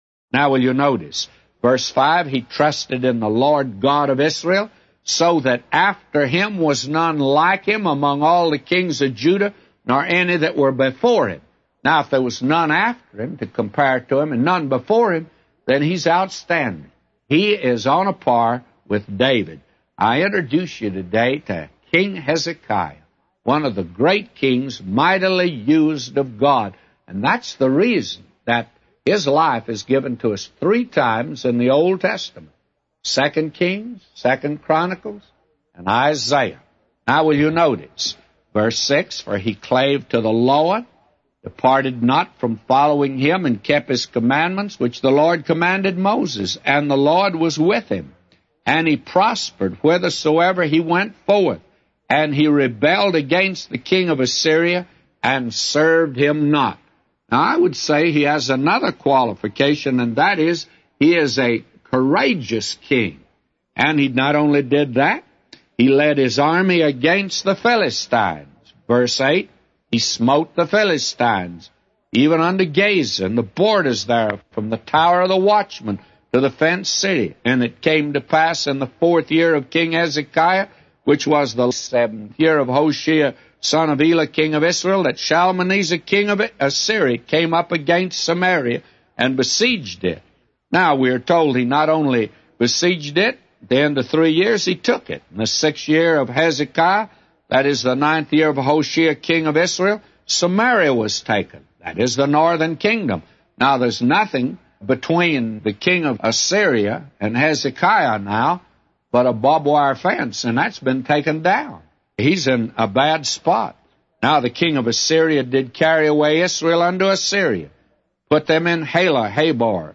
A Commentary By J Vernon MCgee For 2 Kings 18:5-999